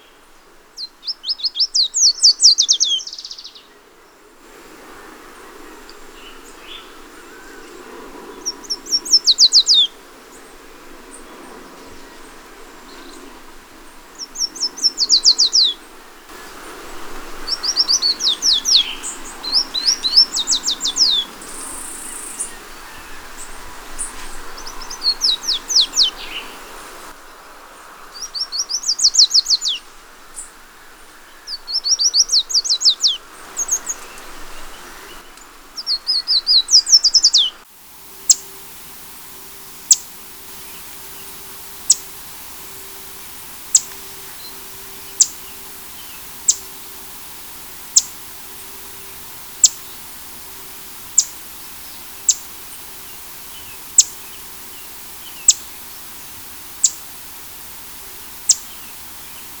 Yellow Warbler
Canario de Mangle,
Dendroica petechia
VOZ: El canto es una serie que se acelera de notas dulces, a menudo ligadas al final.